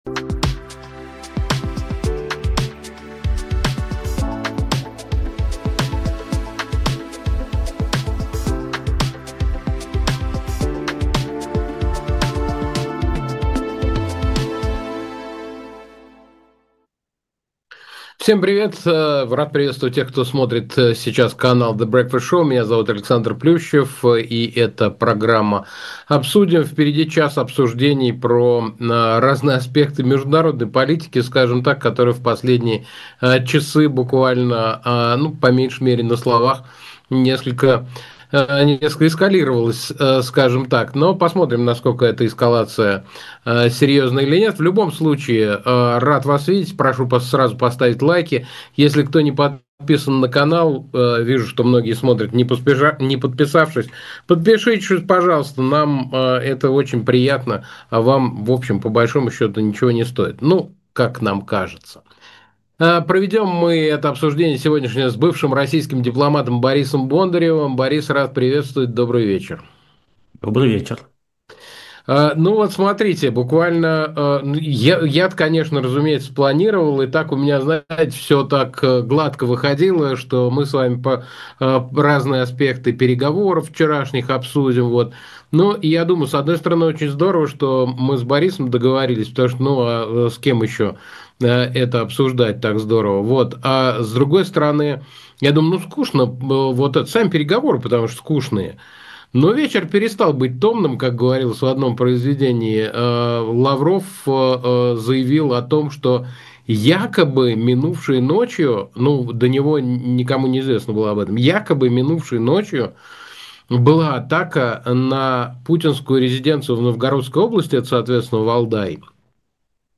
Эфир ведёт Александр Плющев